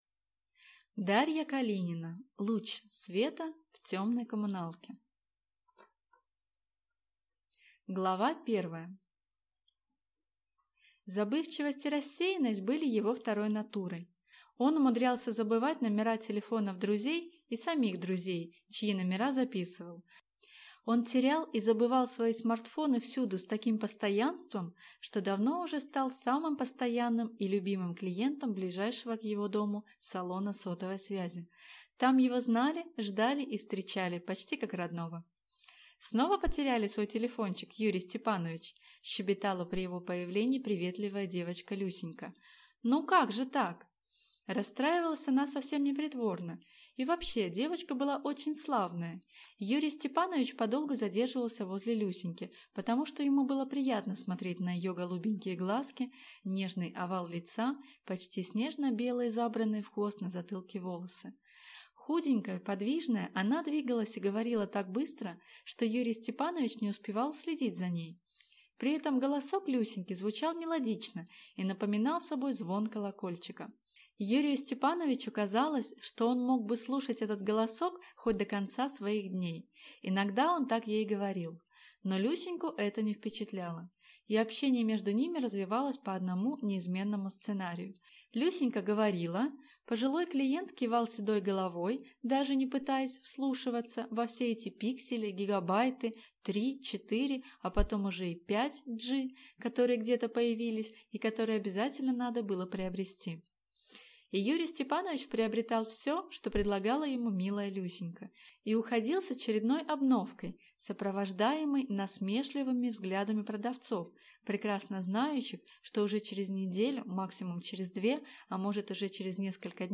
Аудиокнига Луч света в темной коммуналке | Библиотека аудиокниг